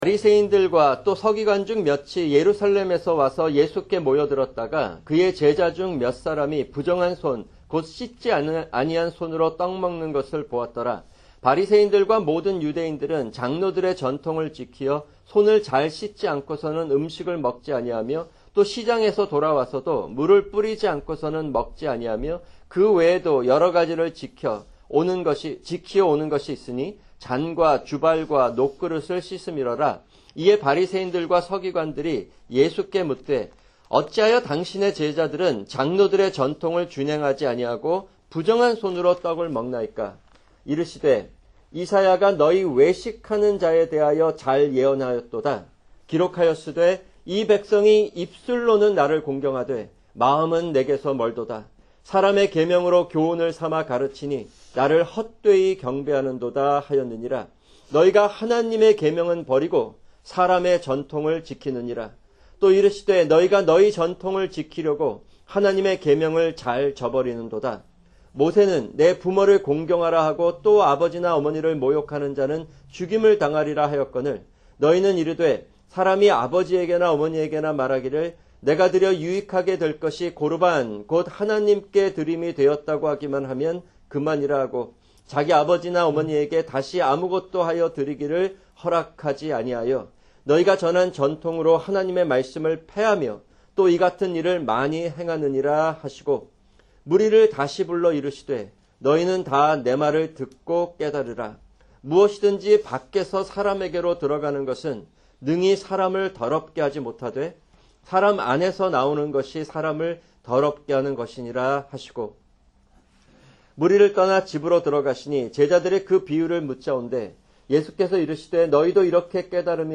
[주일 설교] 마가복음(31) 7:1-23(1)